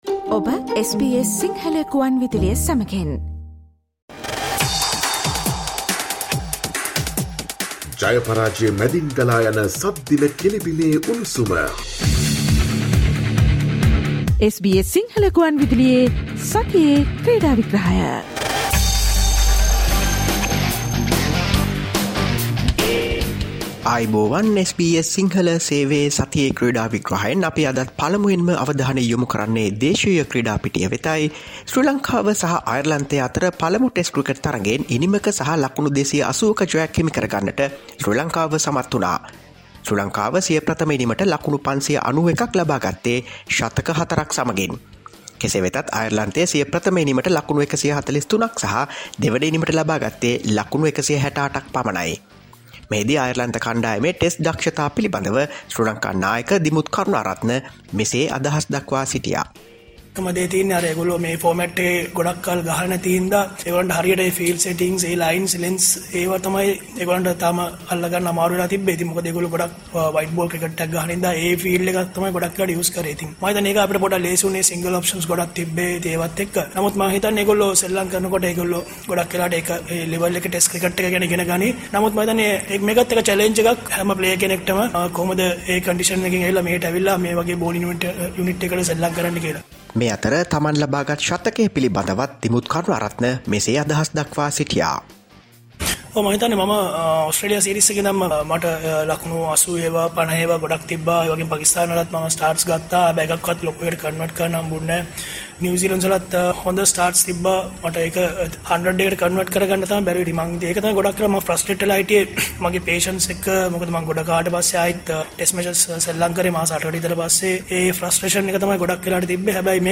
Listen to the SBS Sinhala Radio weekly sports highlights every Friday from 11 am onwards.